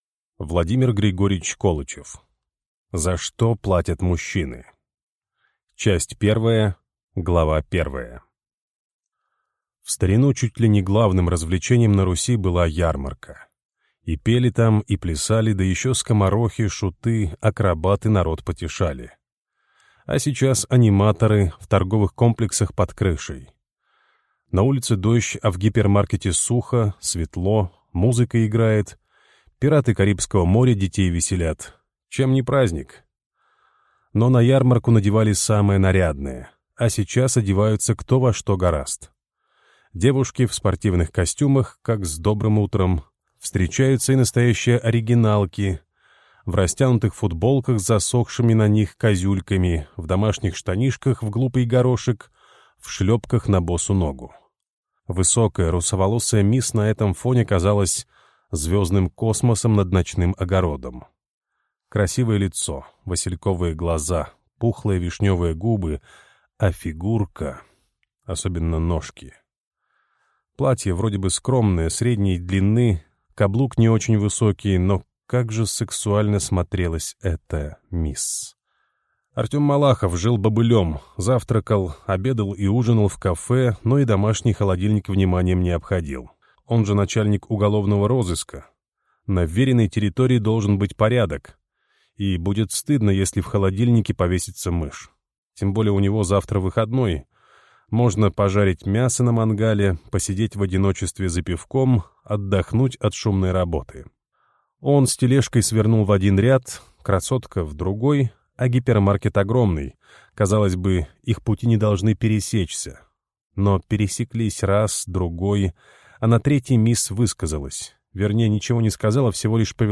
Аудиокнига За что платят мужчины | Библиотека аудиокниг